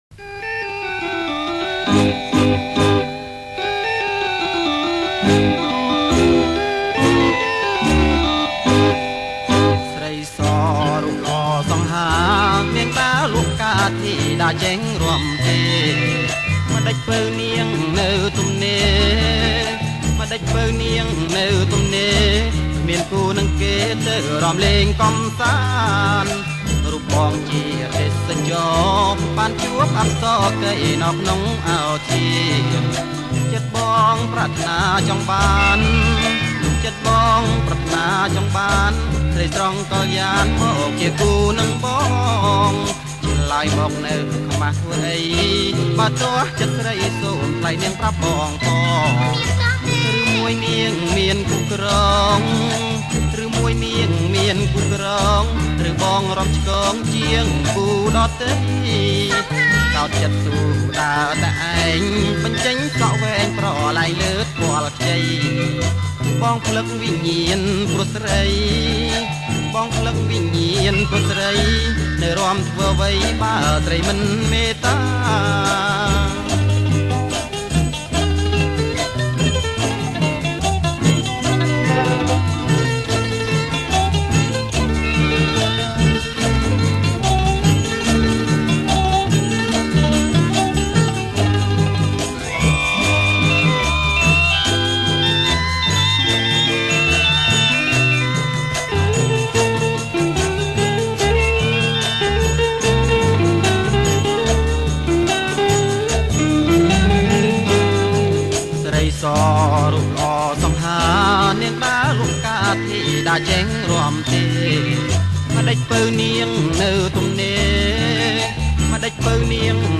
• ប្រគំជាចង្វាក់ រាំវង់